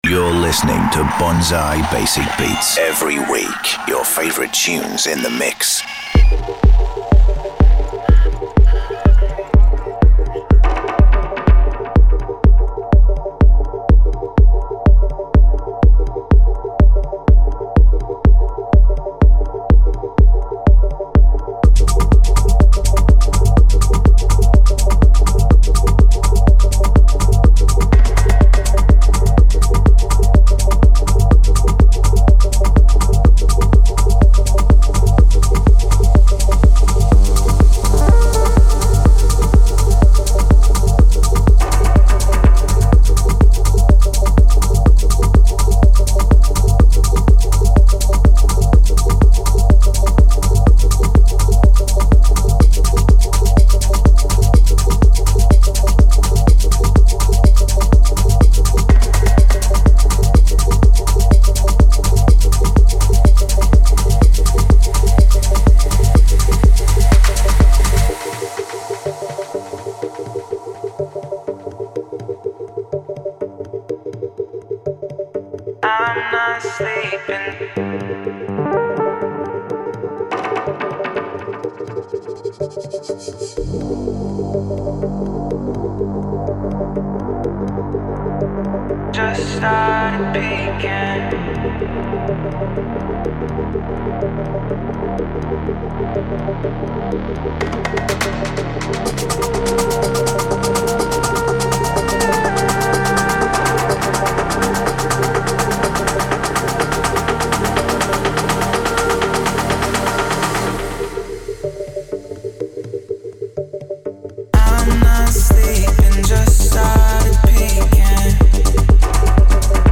primed to spark the weekend’s party atmosphere.